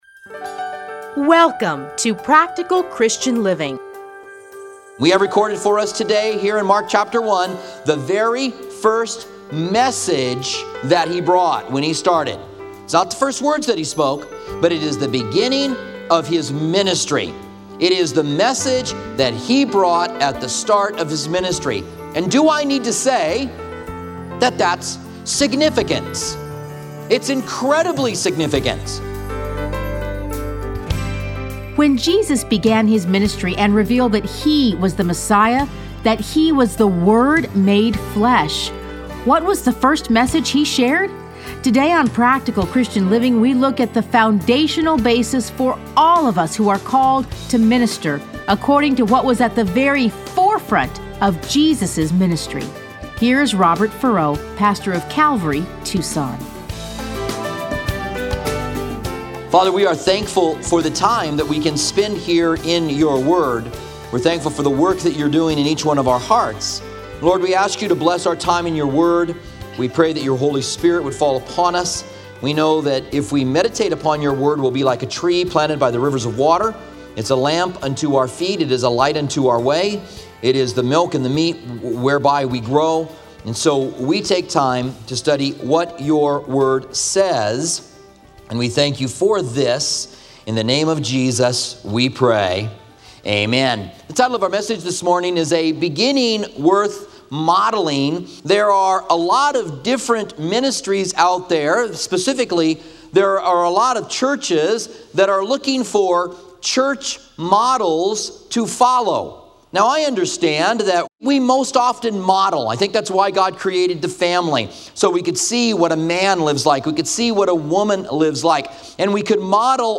Listen to a teaching from Mark 1:14-15.